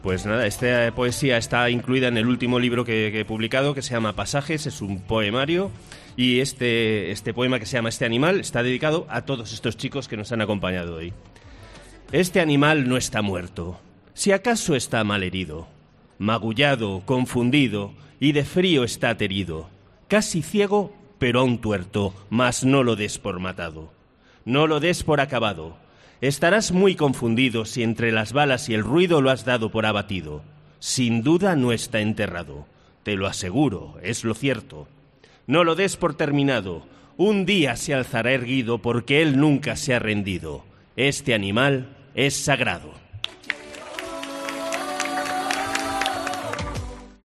recita su poema 'Este animal'